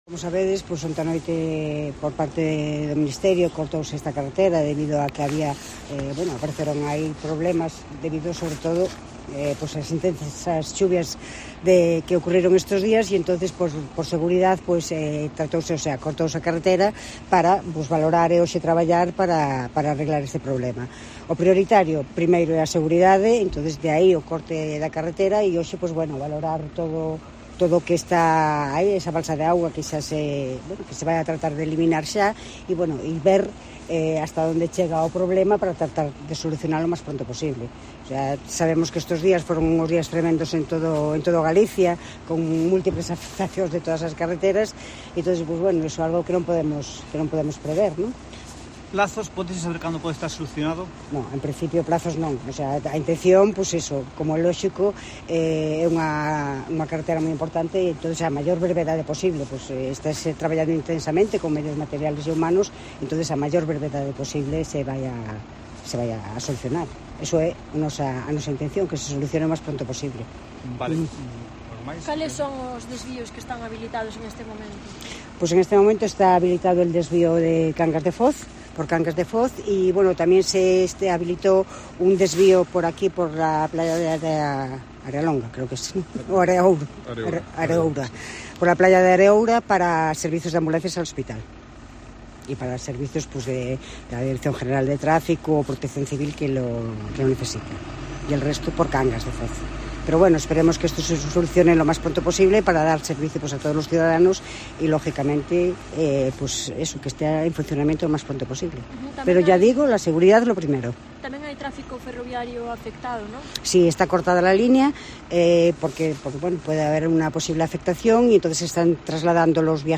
Declaraciones de la subdelegada de Gobierno y los alcaldes de Foz y Burela sobre el socavón